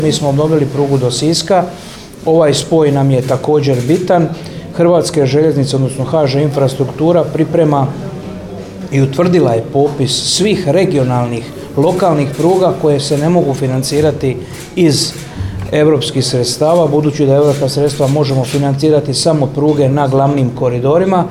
Petrinja uskoro može očekivati prugu, potvrdio je ovo, tijekom radnog posjeta Županiji, odgovarajući na novinarsko pitanje, potpredsjednik Vlade, ministar mora, prometa i infrastrukture Oleg Butković